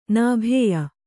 ♪ nābhēya